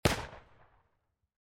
shooter / sound / weapons / gun.ogg
gun.ogg